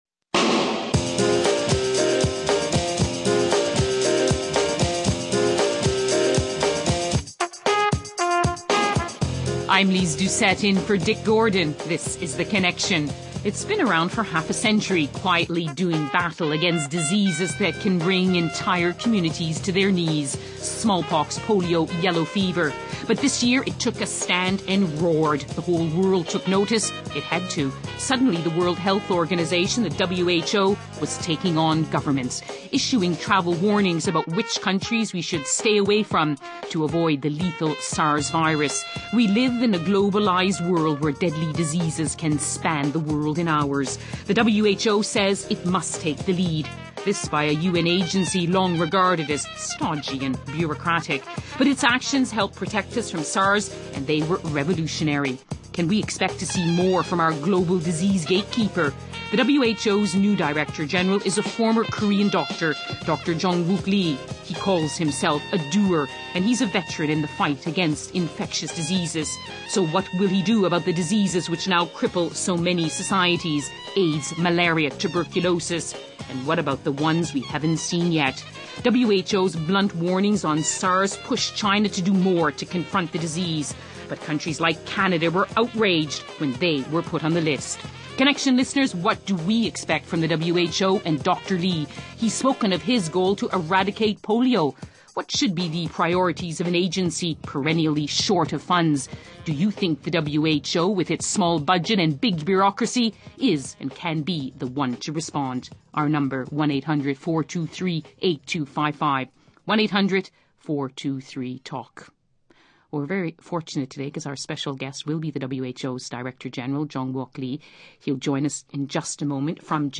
He’s the WHO’s new Director General and wants to take his agency from headquarters to the field in the battle for global health. A conversation with the new disease gatekeeper.